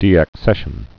(dēăk-sĕshən)